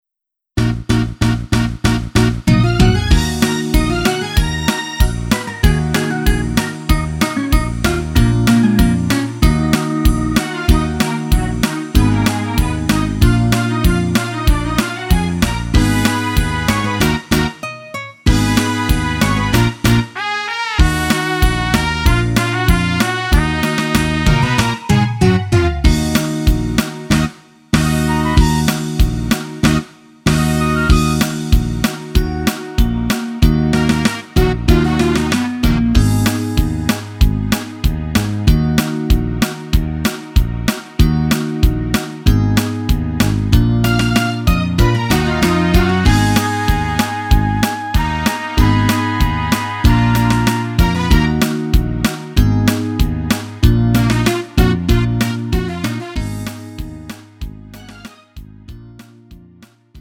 음정 원키 3:05
장르 가요 구분 Lite MR
Lite MR은 저렴한 가격에 간단한 연습이나 취미용으로 활용할 수 있는 가벼운 반주입니다.